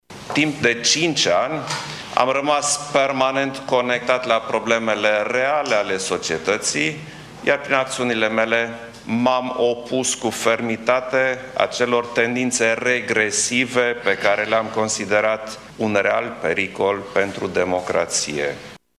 La finalul ceremoniei solemne, președintele a declarat că votul pe care i l-au acordat românii reprezintă pentru el o mare responsabilitate și că primii săi cinci ani la Palatul Cotroceni au fost plini de provocări: